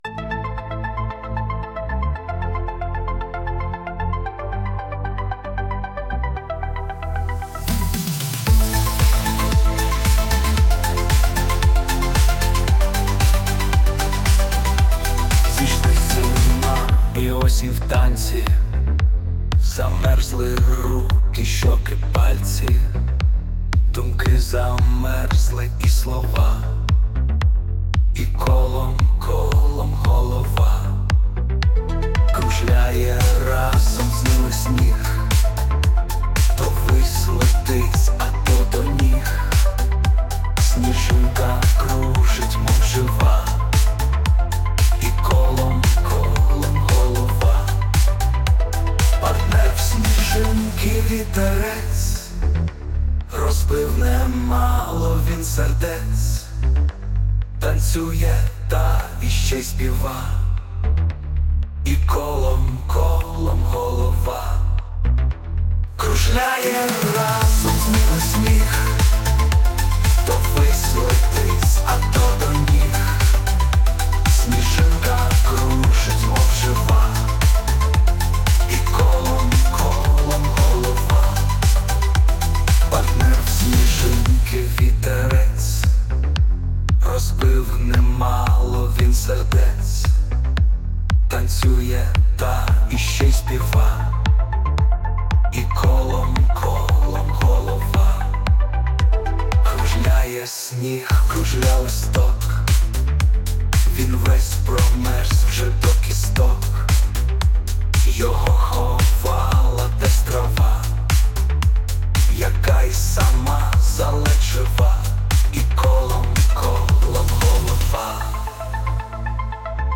ТИП: Пісня
СТИЛЬОВІ ЖАНРИ: Ліричний
Гарна танцювальна музика, під ритмічну зимову лірику.